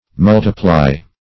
multiply - definition of multiply - synonyms, pronunciation, spelling from Free Dictionary
Multiply \Mul"ti*ply\, v. t. [imp. & p. p. Multiplied; p. pr.